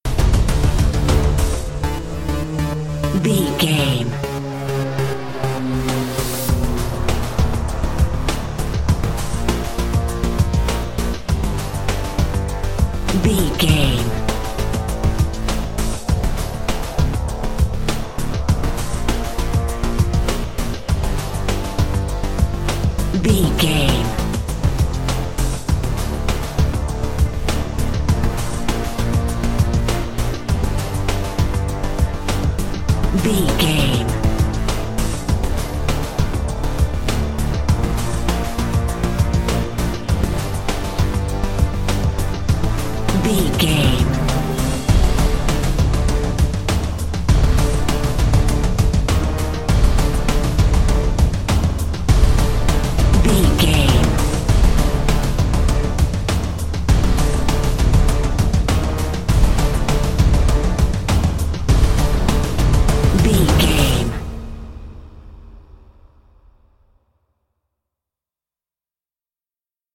Epic / Action
Aeolian/Minor
strings
drums
drum machine
synthesiser
brass
orchestral
orchestral hybrid
dubstep
aggressive
energetic
intense
bass
synth effects
wobbles
heroic
driving drum beat
epic